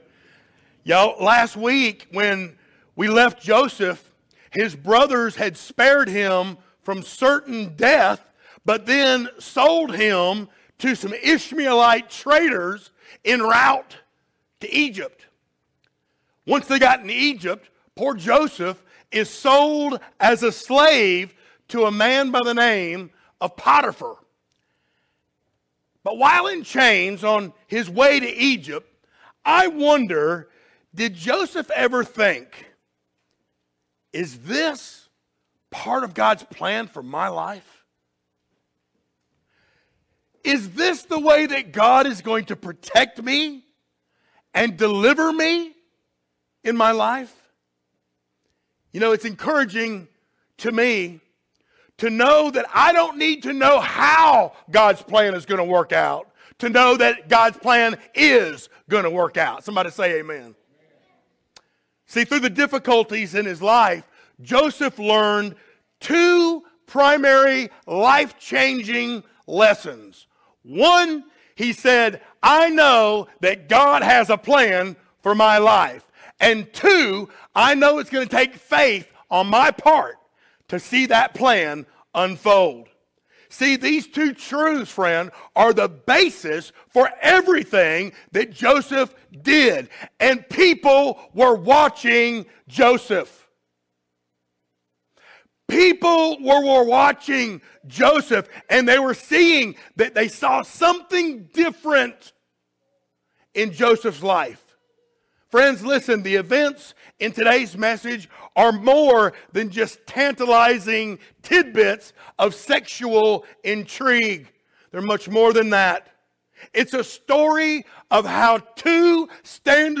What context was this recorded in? Genesis 39:1-20 Service Type: Sunday Morning Download Files Notes Topics